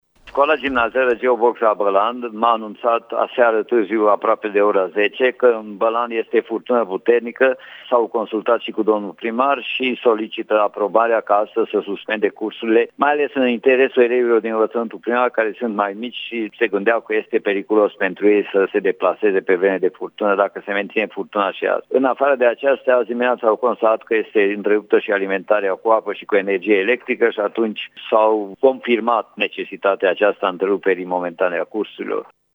Alimentarea cu apă și curent electric ale unității de învățământ au fost întrerupte din cauza unei furtuni violente de aseară, a explicat inspectorul școlar general al județului Harghita, Görbe Péter: